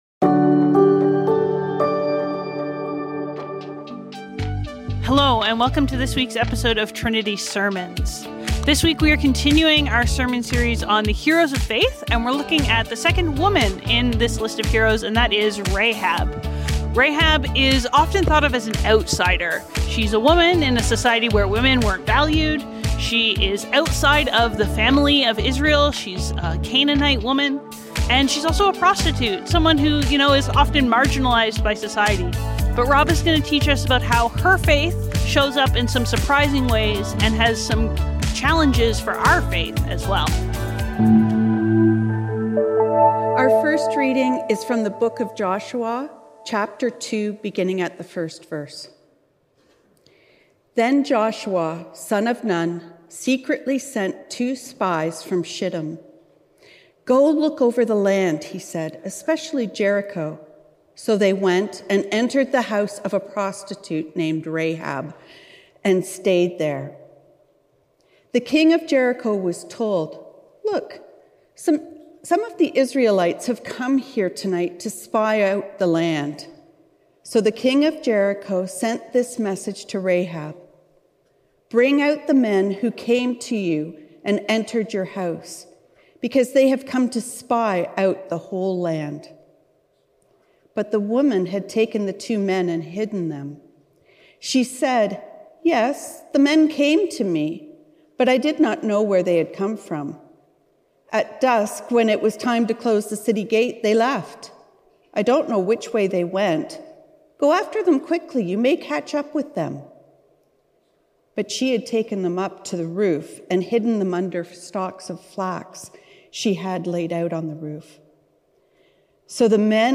Trinity Streetsville - Rahab: Faith that Surprises | Heroes of Faith | Trinity Sermons